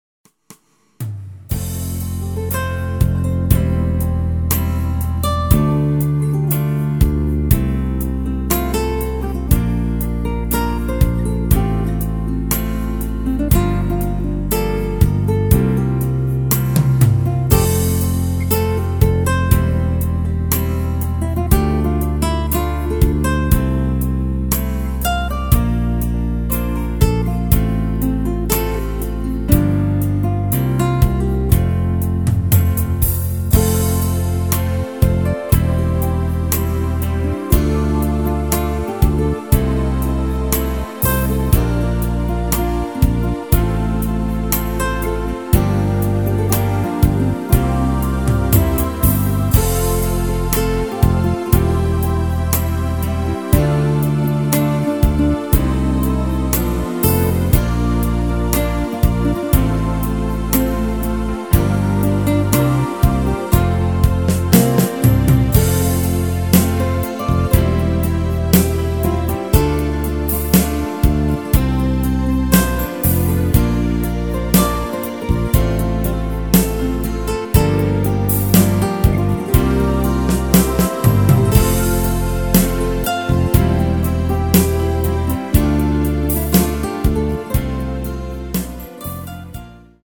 • Tonart: F Dur
• Art: Bandplayback
• Das Instrumental beinhaltet keine Leadstimme
Lediglich die Demos sind mit einem Fade-In/Out versehen.
Klavier / Streicher